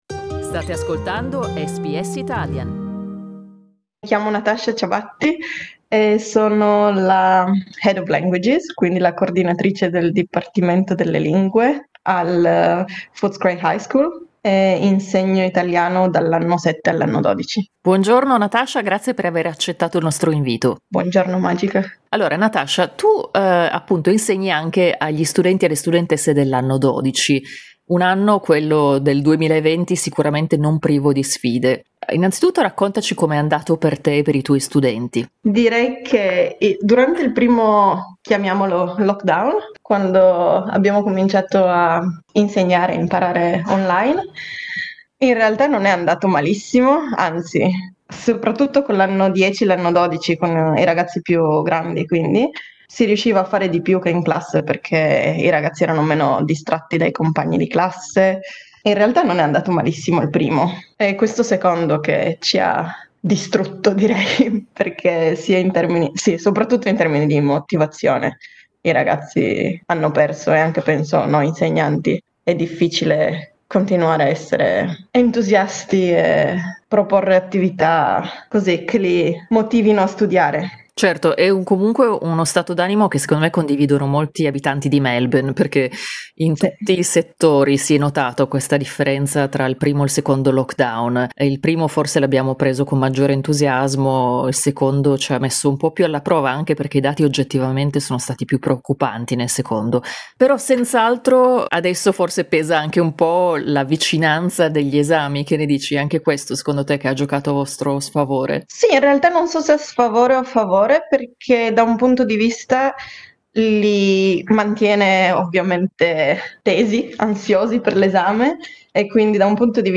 Ascolta l'intervista: LISTEN TO I preparativi per l'anno 12 a Melbourne, dopo due lockdown SBS Italian 11:22 Italian Le persone in Australia devono stare ad almeno 1,5 metri di distanza dagli altri.